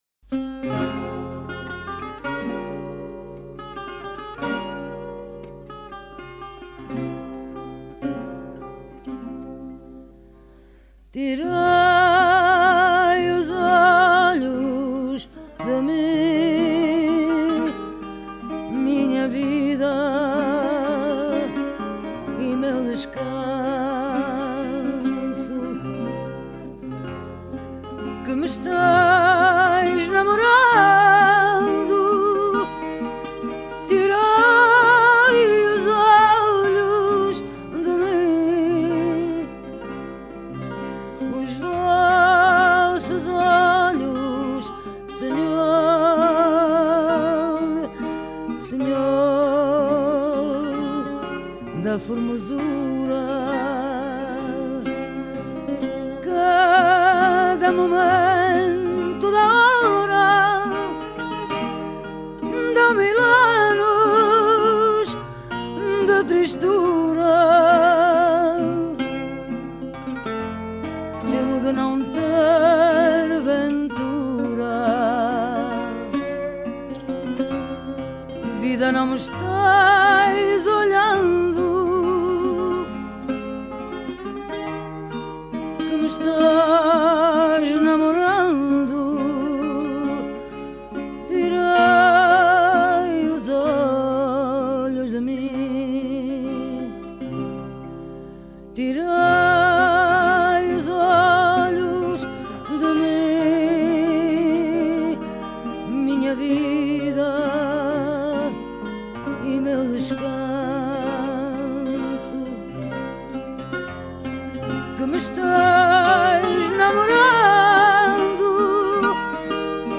chant
guitare portugaise